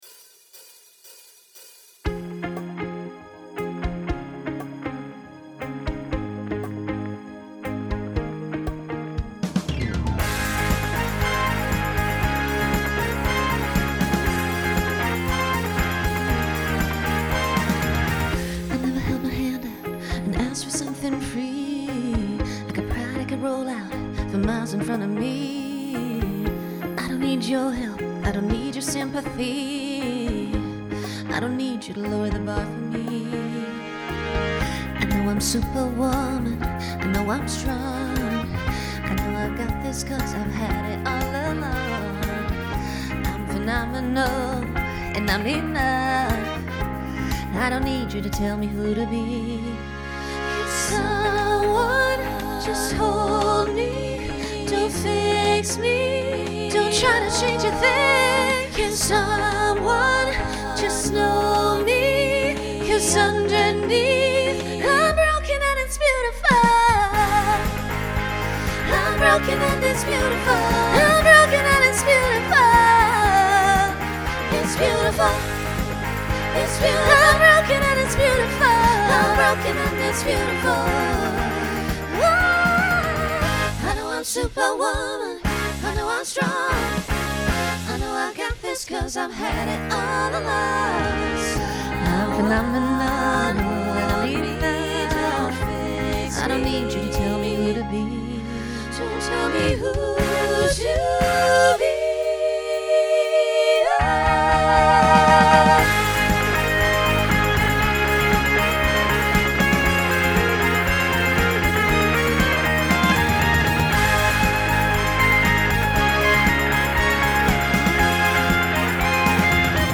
Pop/Dance Instrumental combo
Solo Feature Voicing SSA